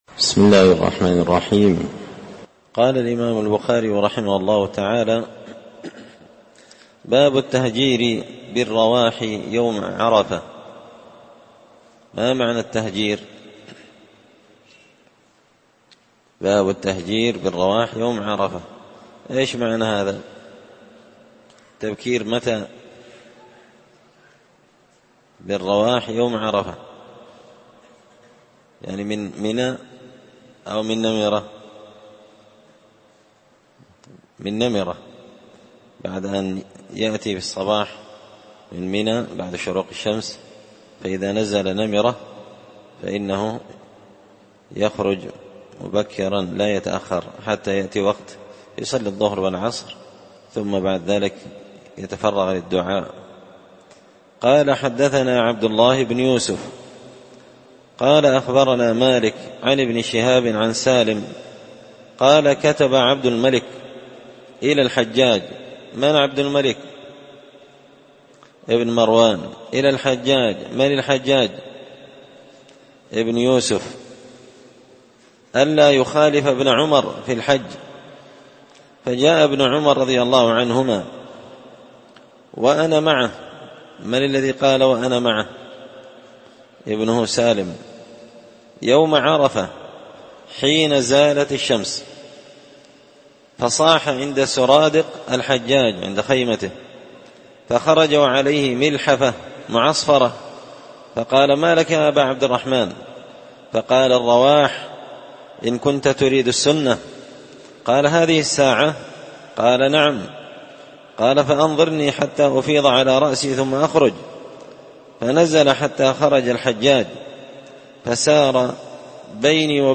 ألقيت هذه الدروس في # دار الحديث السلفية بقشن بالمهرة اليمن مسجد الفرقان